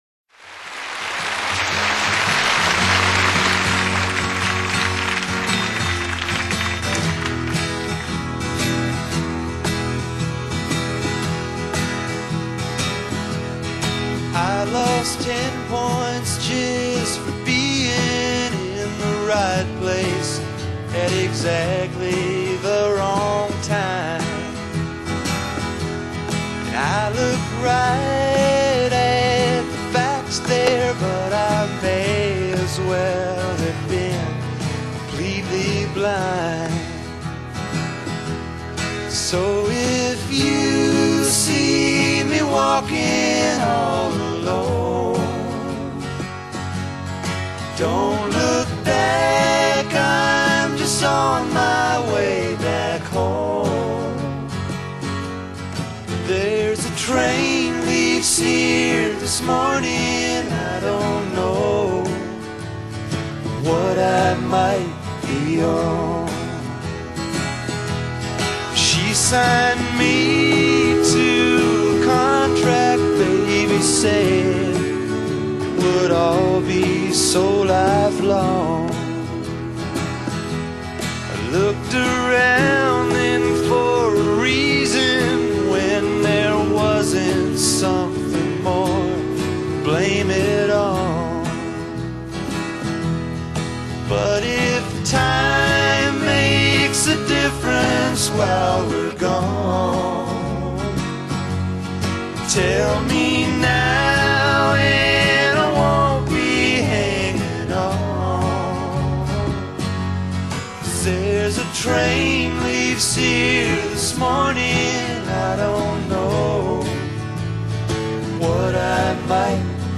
Live at Paris Theatre
Country/Folk/Hard Rock hybrid